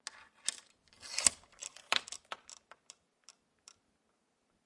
随机的 "门锁滑链异响
描述：门锁滑动链拨浪鼓.flac
Tag: 车门 滑动 拨浪鼓